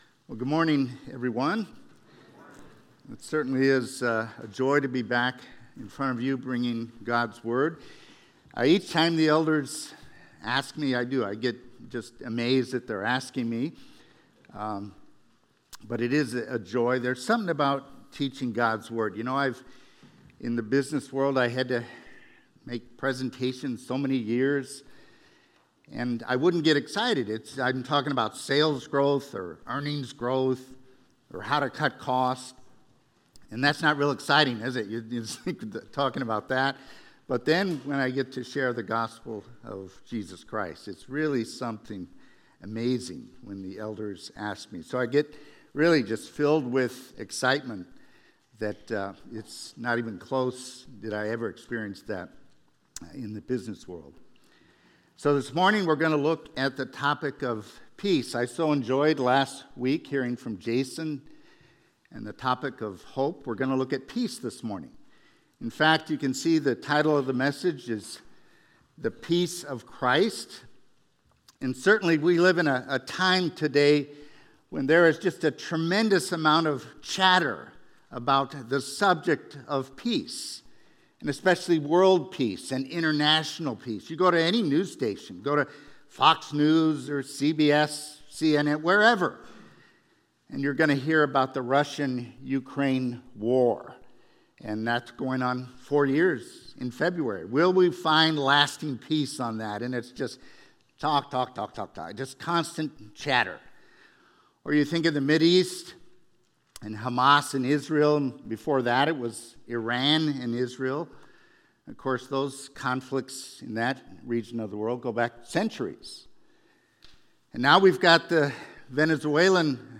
Sermons – Wichita Bible Church